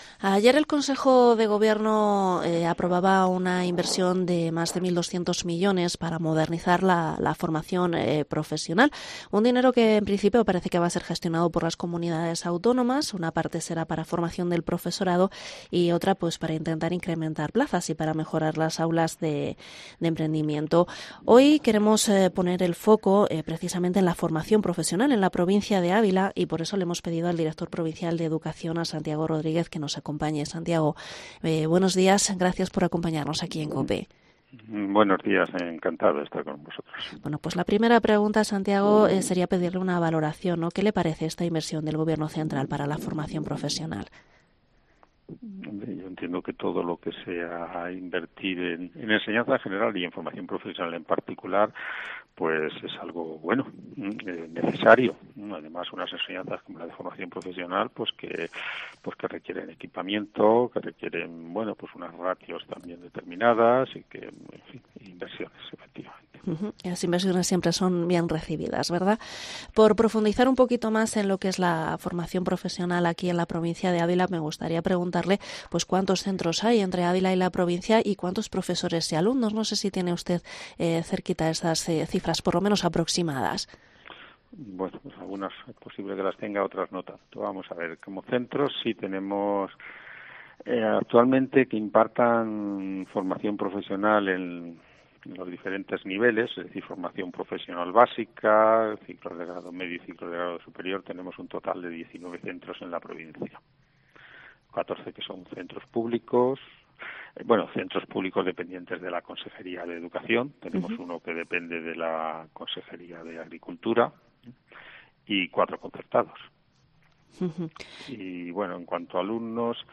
Entrevista director provincial de Educación